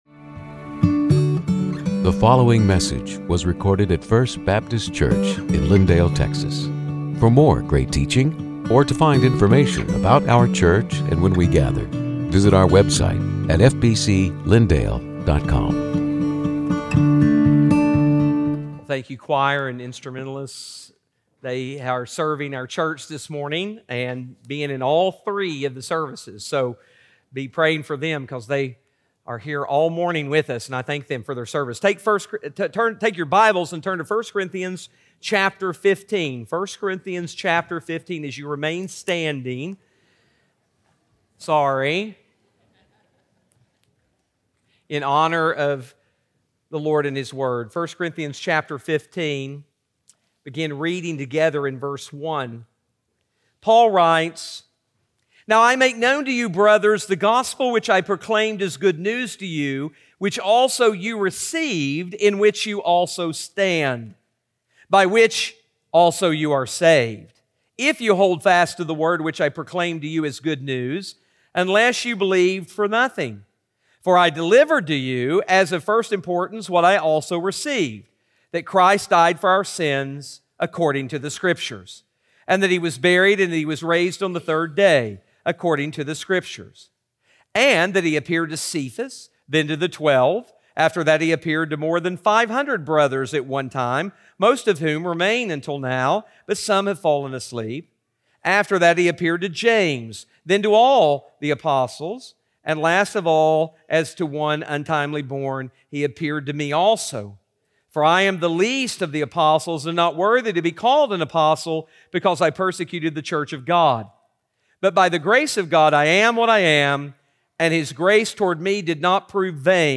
Sermons › Easter 2026 – The Credible and Crucial Resurrection